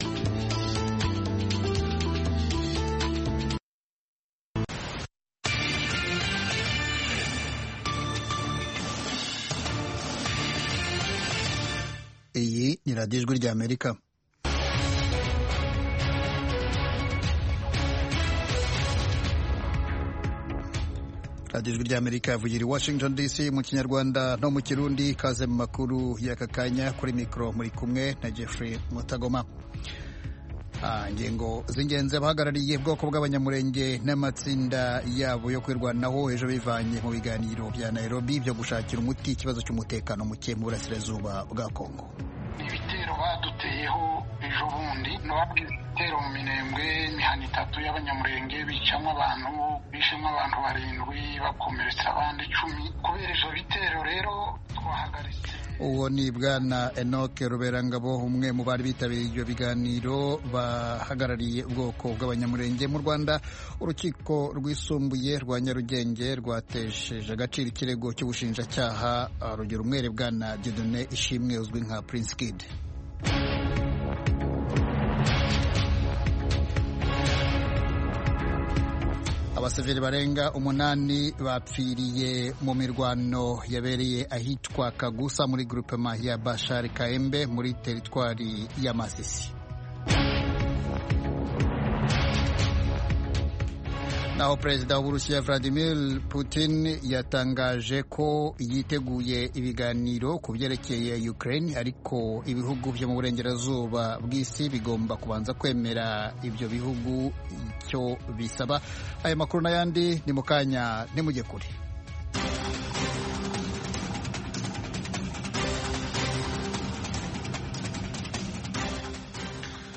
Ejo ni ikiganiro cy'iminota 30 gitegurwa n'urubyiruko rwo mu Rwanda, kibanda ku bibazo binyuranye ruhura na byo. Ibyo birimo kwihangira imirimo, guteza imbere umuco wo kuganiro mu cyubahiro, no gushimangira ubumwe n'ubwiyunge mu karere k'ibiyaga bigari by'Afurika.